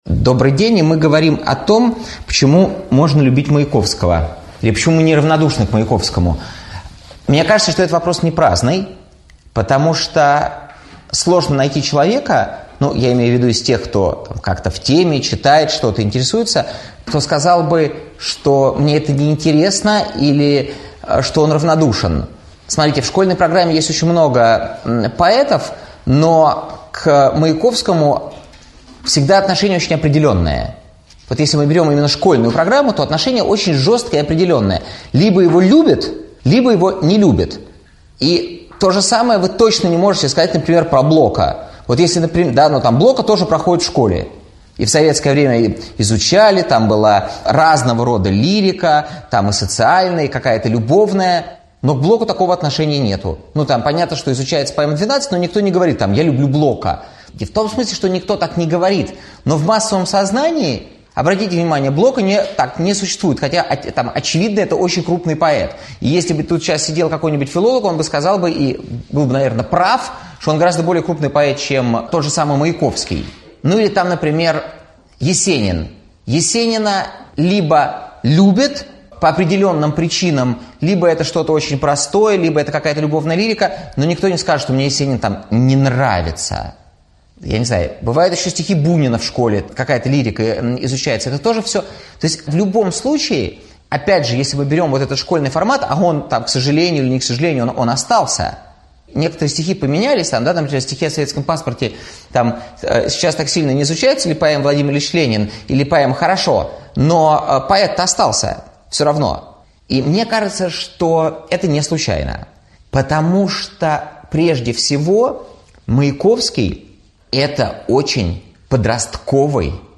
Аудиокнига Лирика Маяковского: поэзия, не совместимая с жизнью | Библиотека аудиокниг